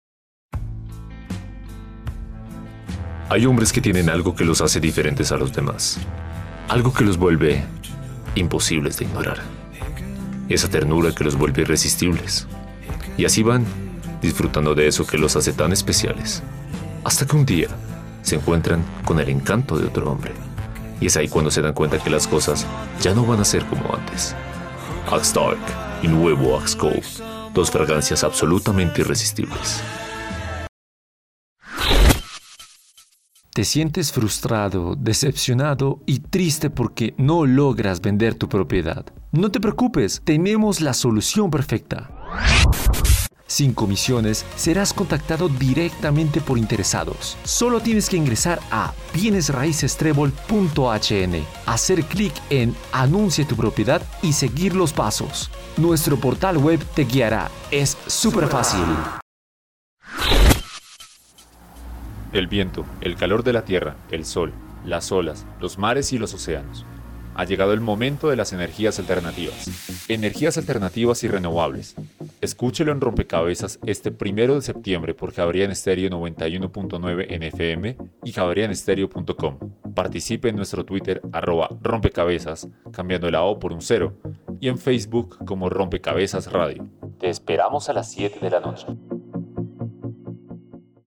Kein Dialekt
Sprechprobe: eLearning (Muttersprache):